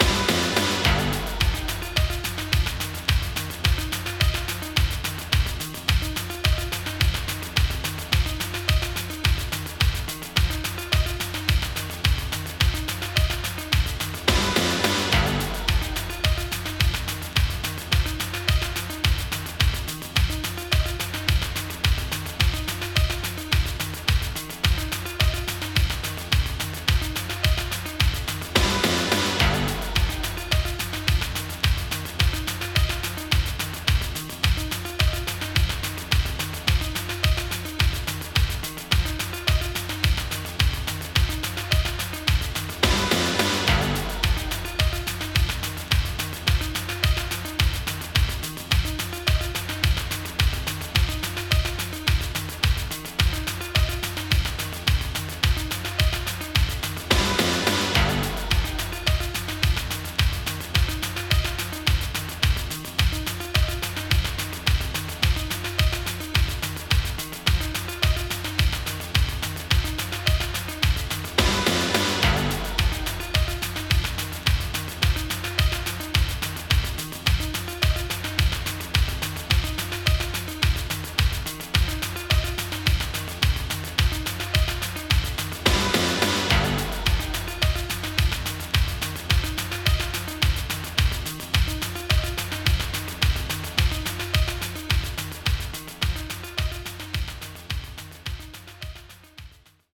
proto-trance_loop.mp3